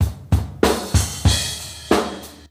Beatcrash.wav